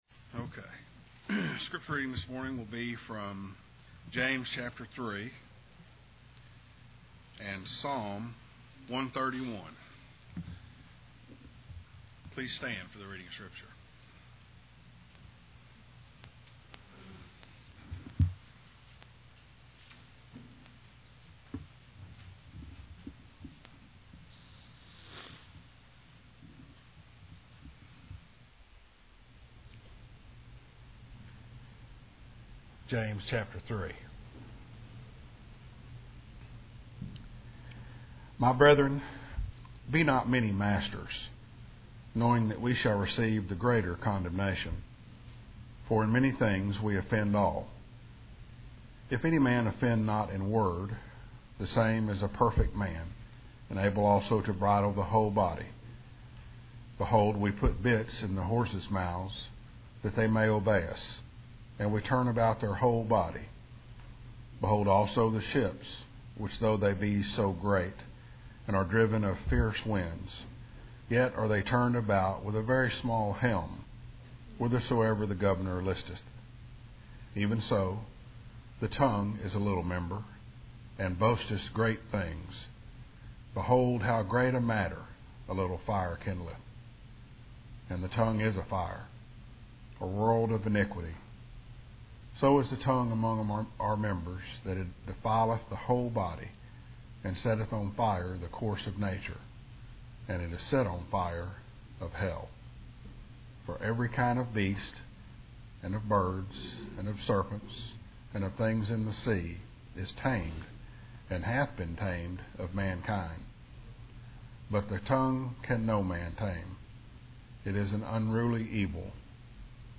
Explore our sermon library below to play, download, and share messages from McKinney Bible Church.